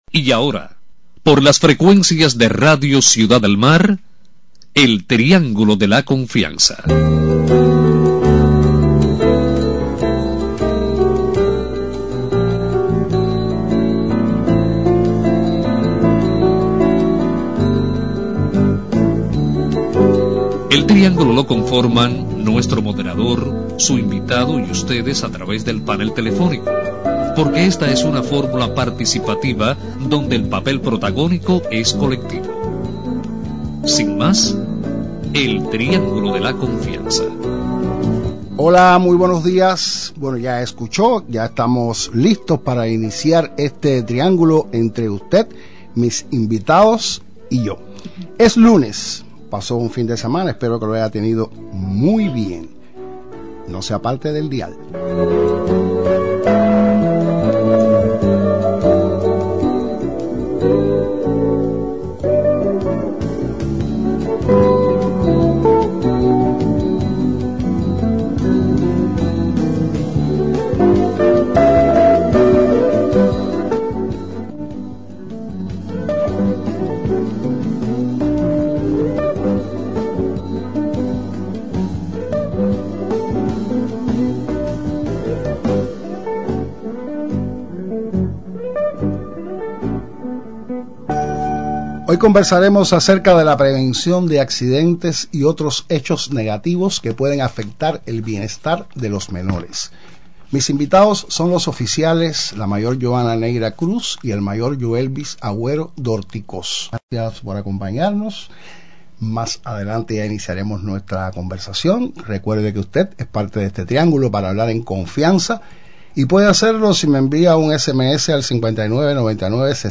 Prevención de accidentes y otros hechos negativos que pueden afectar el bienestar de los menores durante el verano es el tema que propone el Triángulo de la confianza con la participación de oficiales del Ministerio del Interior.